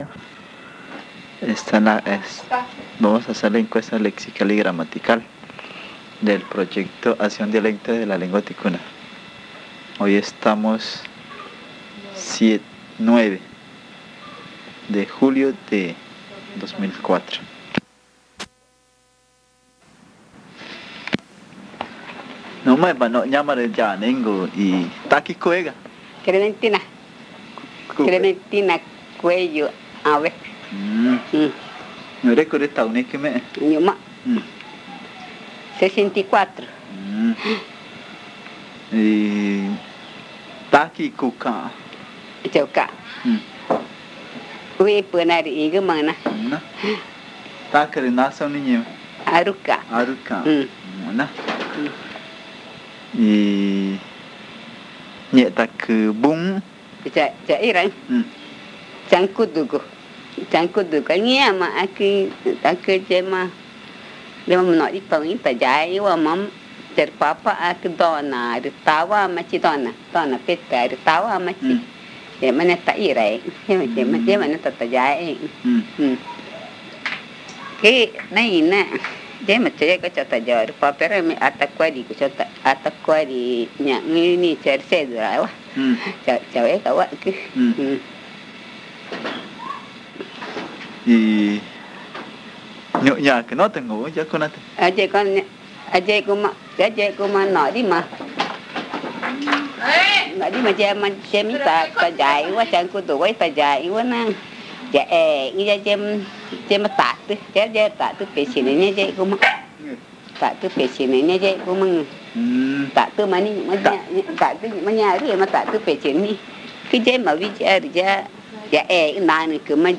El audio contiene los lados A y B.